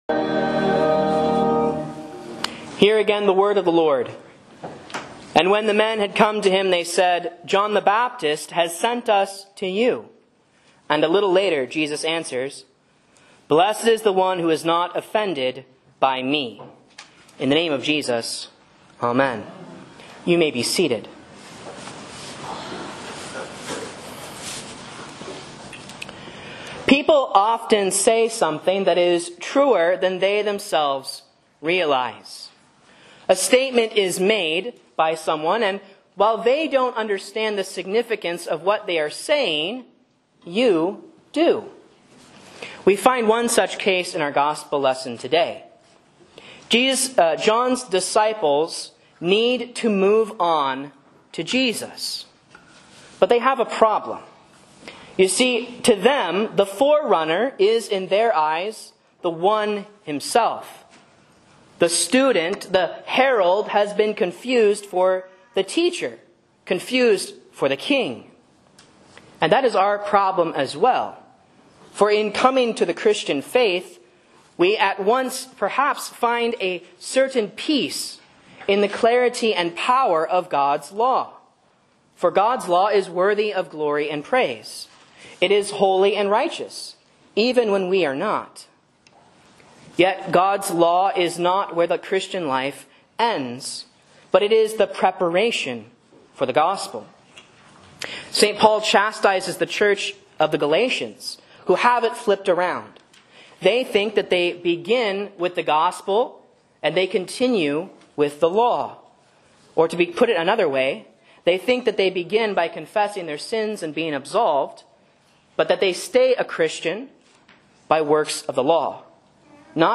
Sermons and Lessons from Faith Lutheran Church, Rogue River, OR
Don't Be Offended By Jesus' Preaching 2024-12-15 A Sermon on Luke 7.18-28 Download Filetype: MP3 - Size: 3 MB - Duration: 16:19m (960 kbps 44100 Hz)